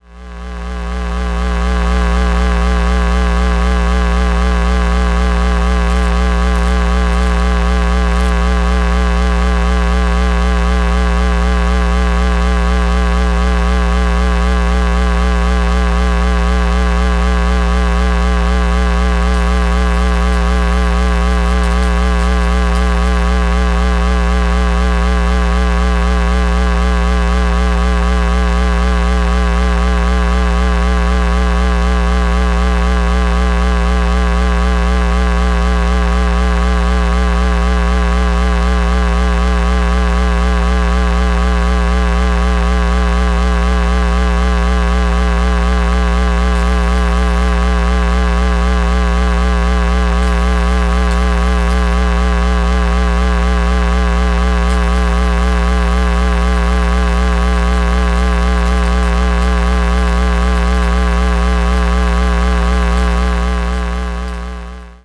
What you're about to hear is AC hum recorded while this is going on. UPS's do not like what you're about to hear, almost certainly because the AC frequency is wobbling back and forth all the time.